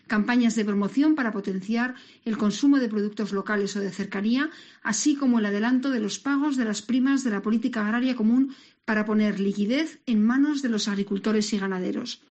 Paloma Martín, consejera de Medio Ambiente, explicando la medida más importante del plan